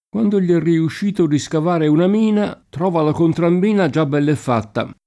contromina [kontrom&na] s. f. (mil.) — anche contrammina [kontramm&na]: quando gli è riuscito di scavare una mina, trova la contrammina già bell’e fatta [
kU#ndo l’l’ $ rriušš&to di Skav#re una m&na, tr0va la kontramm&na J# bbHll e ff#tta] (Manzoni)